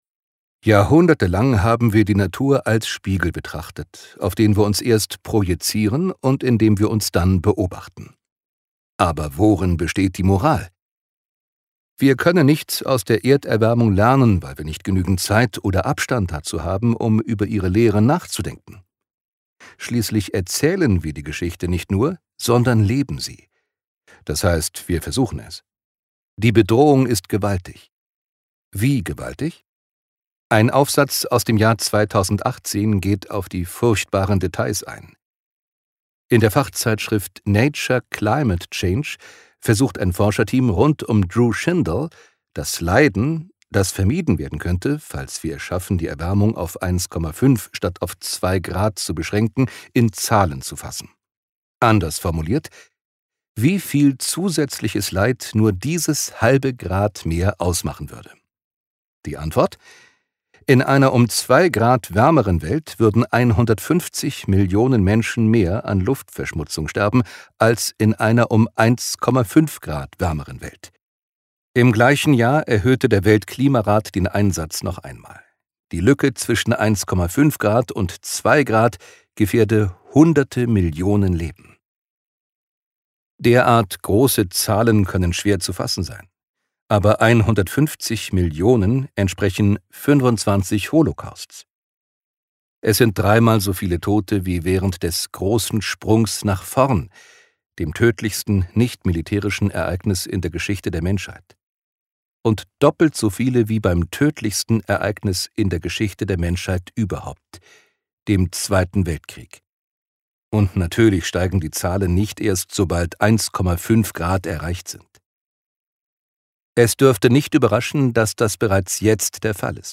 Genre: Lesung.